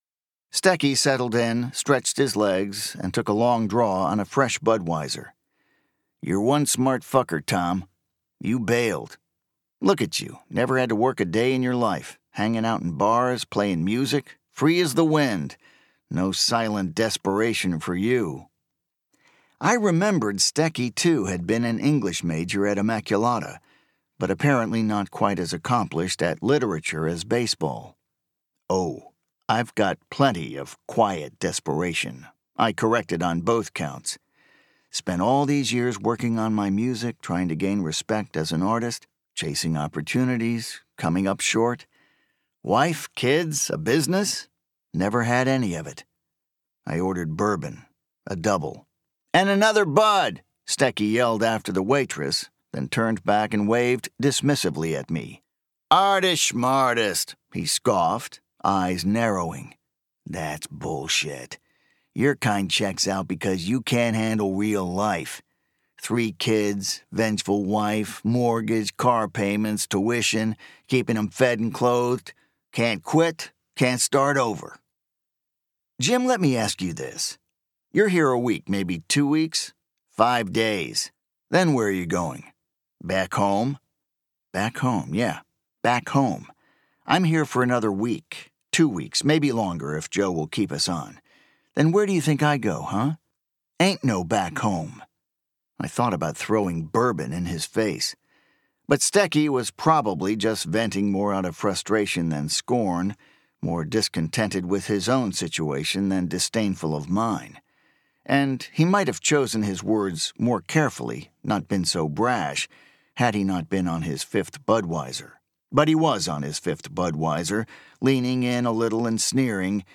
And now, one of the most compelling new audio books.